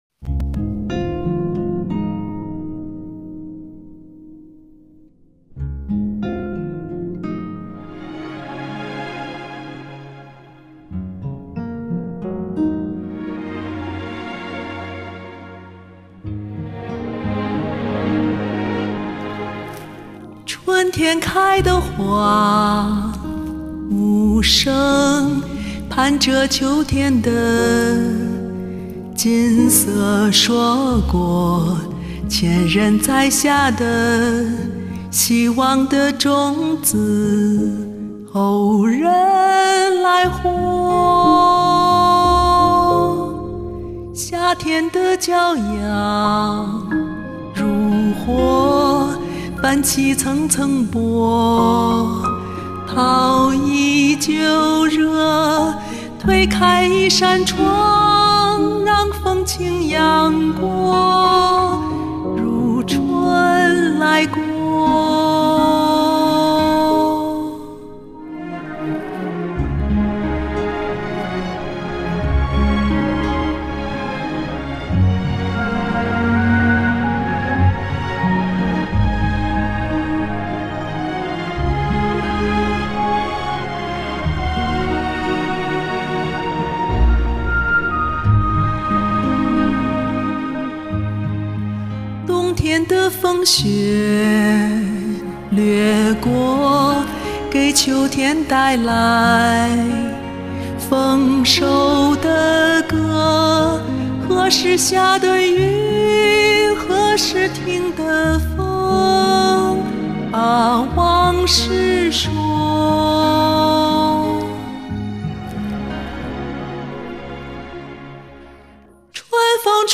歌声舒展悠扬，沁人心脾，歌声所到之处都变得芬芳清爽，
歌声真美。。。
细腻感人，好嗓音，好乐感，非常棒！
旋律很温暖，歌词也有画面感，听完让人心里暖洋洋的。